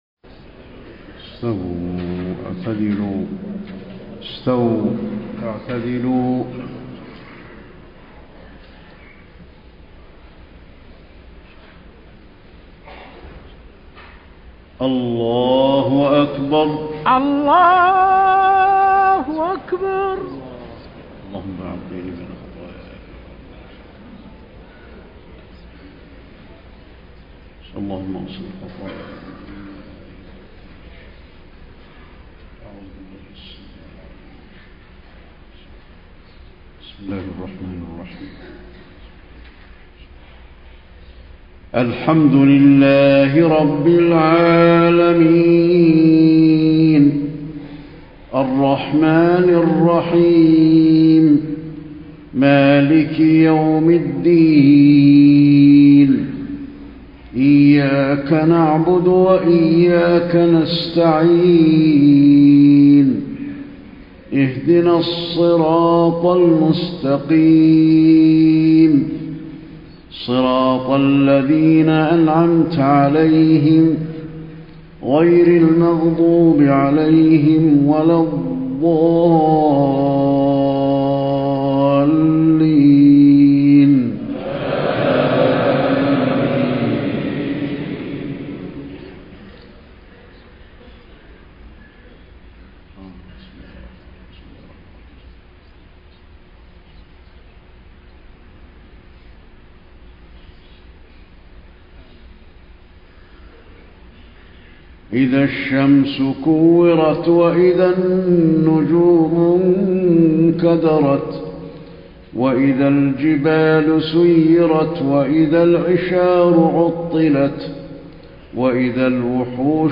صلاة العشاء 6 - 4 - 1434هـ سورتي التكوير و الزلزلة > 1434 🕌 > الفروض - تلاوات الحرمين